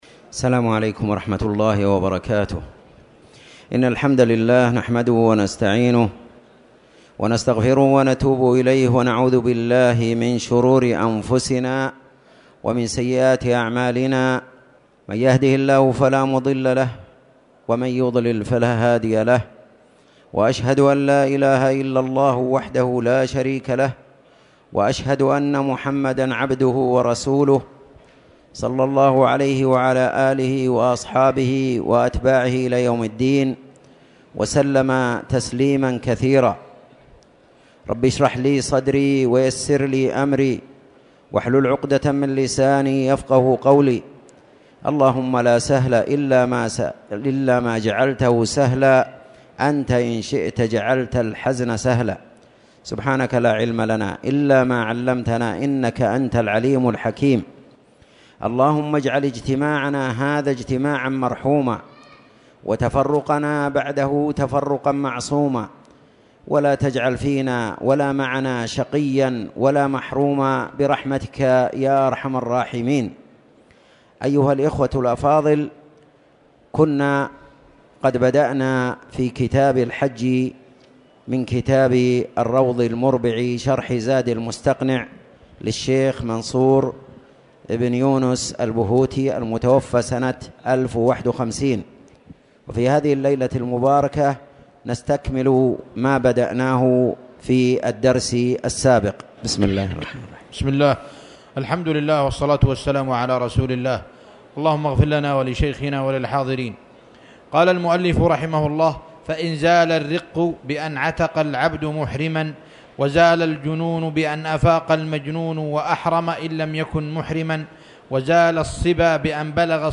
تاريخ النشر ٧ جمادى الآخرة ١٤٣٨ هـ المكان: المسجد الحرام الشيخ